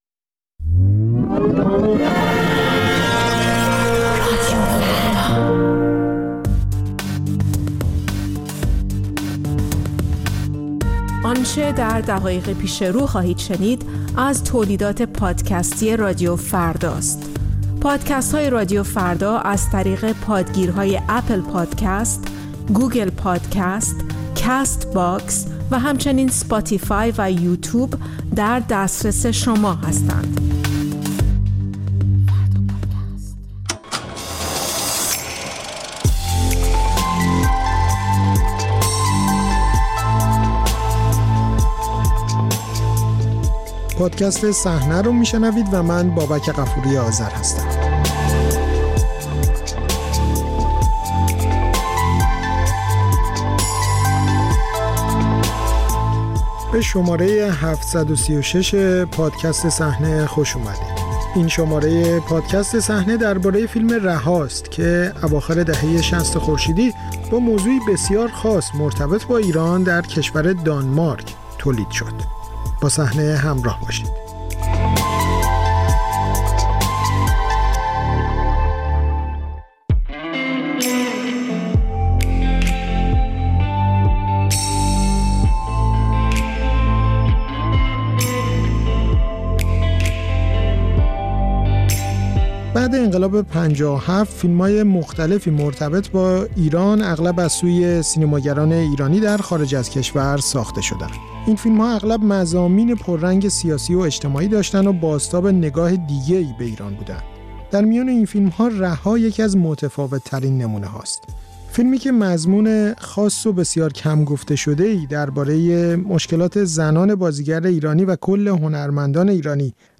برگی از اوراق گمشدۀ فیلمی به نام «رها» در گفت‌وگو با شهره آغداشلو